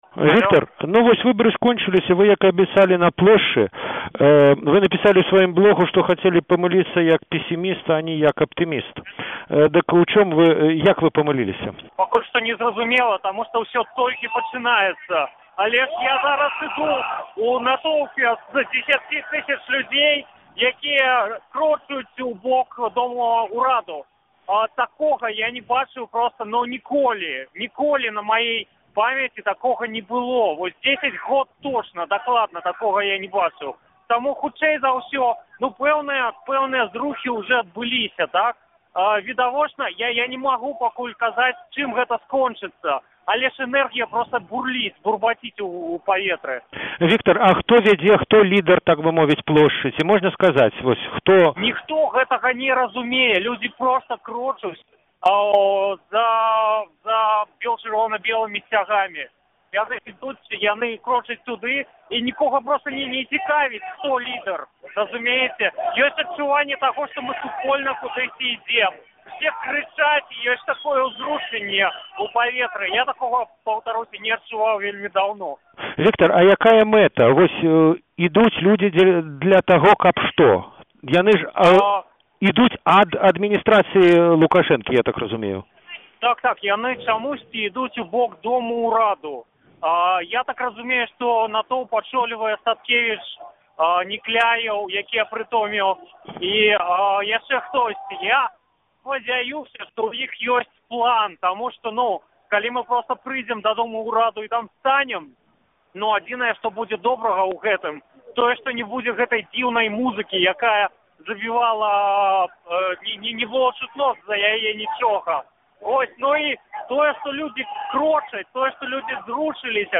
Гутарка з Віктарам Марціновічам